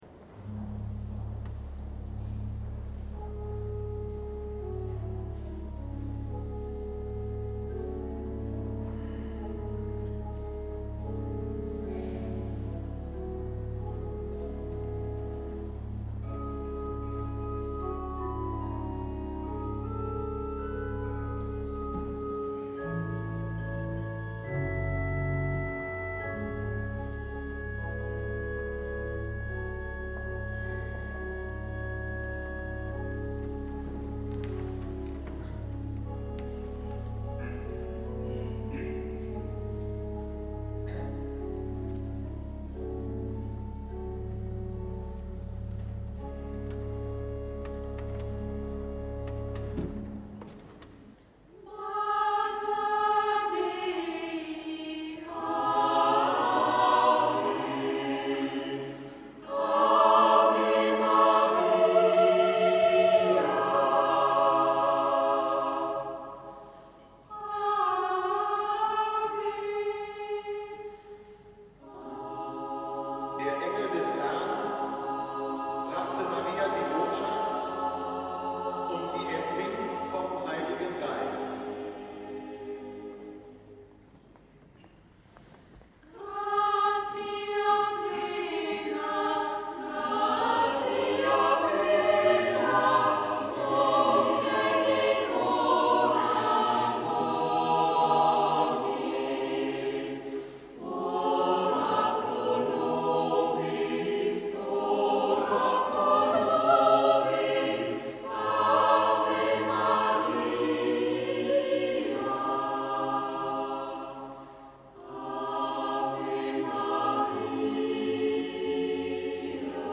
Hauptoktav 2011 Mit dem Hochamt am 11. September 2011 kam die Hauptoktav 2011 zu ihrem diesjährigen Höhepunkt.
Projektchor